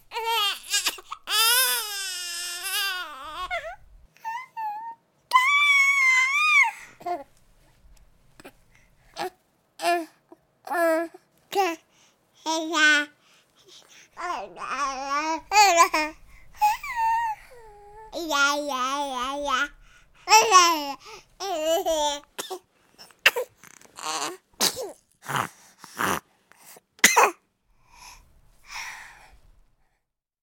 音效介绍 该音效是婴儿2周大和3-6个月大时记录的声音。包括可爱的咕咕，说话，呼吸，努力，叹气，打哈欠，咳嗽，打喷嚏和哭泣。